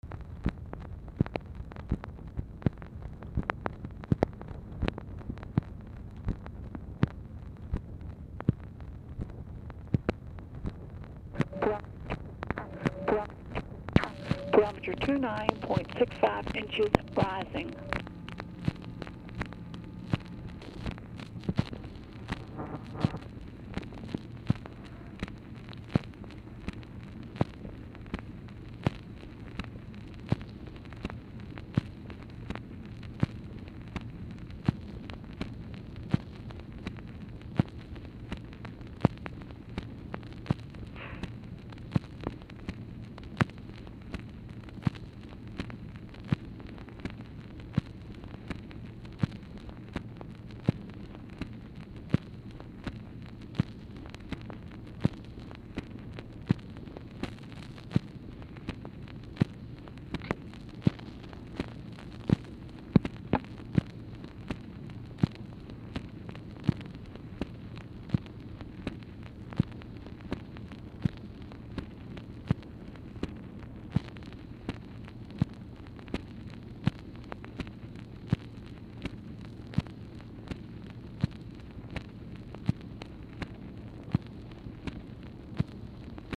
Telephone conversation # 2229, sound recording, WEATHER REPORT
PRIMARILY OFFICE NOISE
Format Dictation belt